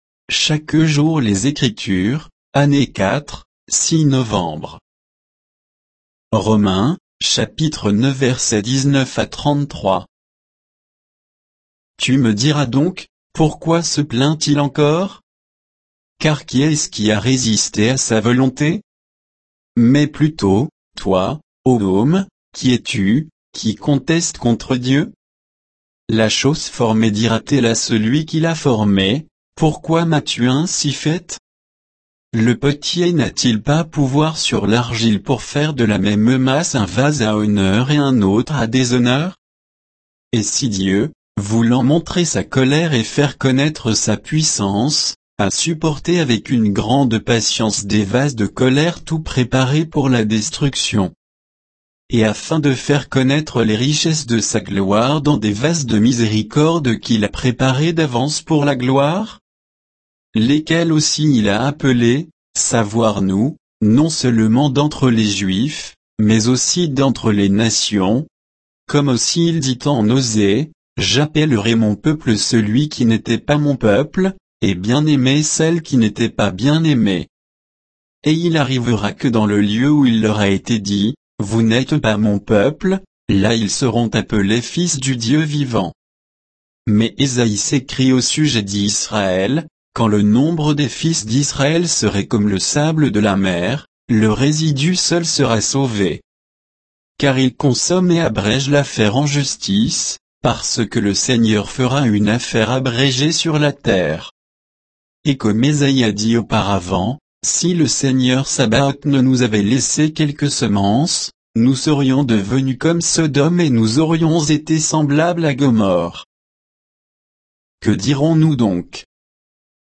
Méditation quoditienne de Chaque jour les Écritures sur Romains 9